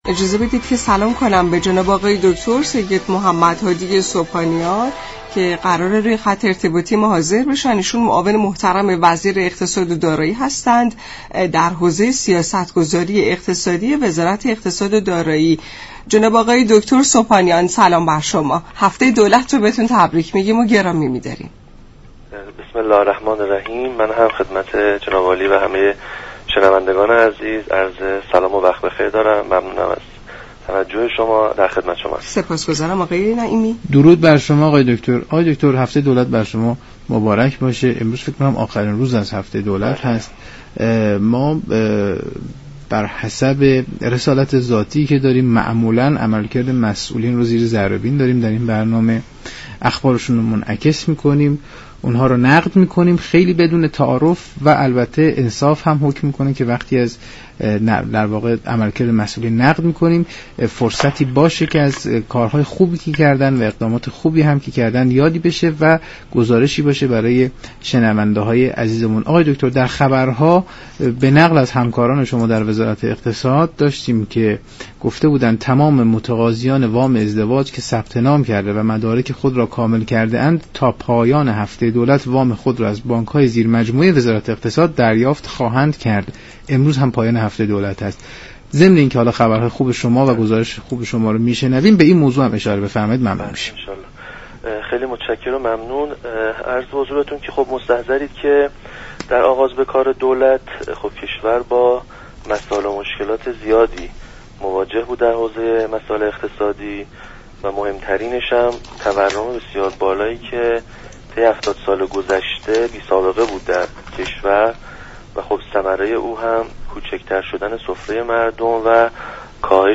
به گزارش شبكه رادیویی ایران، «سید محمد هادی» سبحانیان معاون اقتصادی وزیر اقتصاد و دارایی، در برنامه «نمودار» درباره دستاوردهای دولت در حوزه اقتصاد گفت: علی رغم همه مشكلات و تحریم های موجود، دولت در یكسال اخیر تورم 59.3 درصدی را به تورم 40 درصدی رسانیده است.